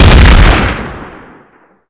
sol_reklam_link sag_reklam_link Warrock Oyun Dosyalar� Ana Sayfa > Sound > Weapons > BARRETT_M82 Dosya Ad� Boyutu Son D�zenleme ..
WR_Fire.wav